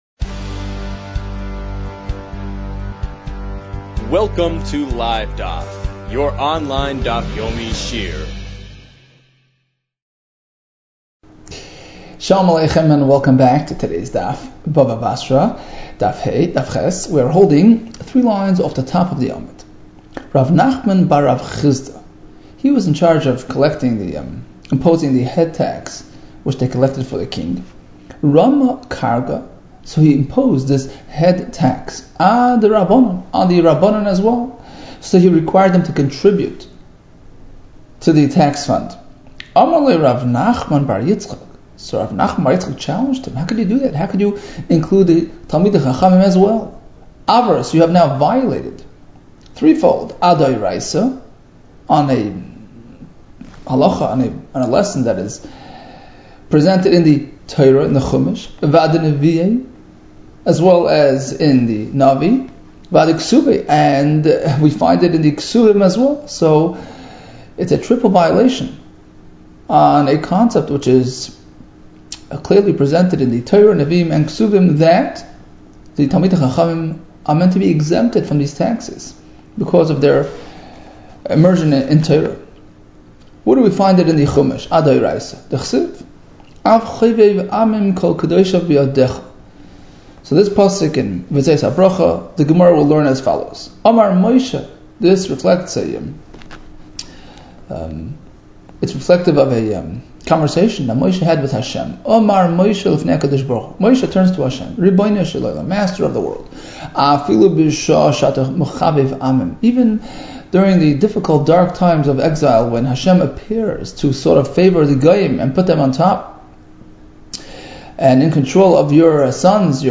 Bava Basra 7 - בבא בתרא ז | Daf Yomi Online Shiur | Livedaf